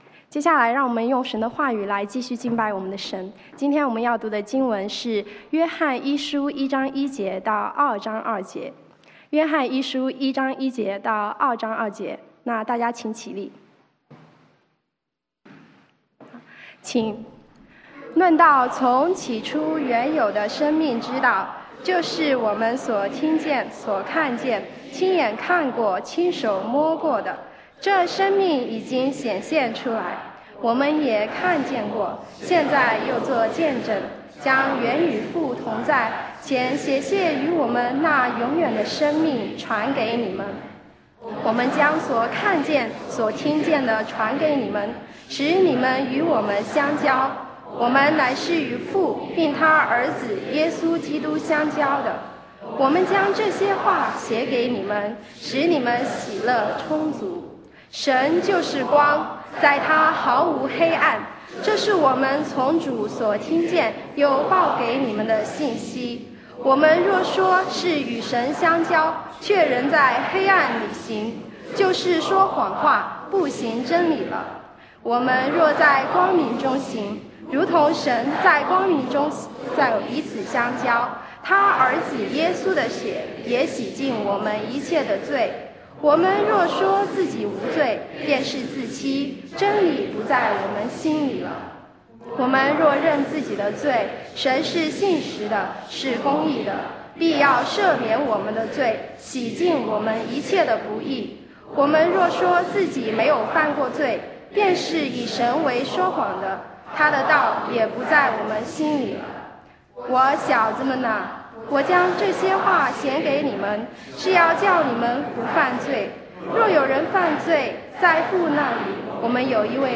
Service Type: 主日證道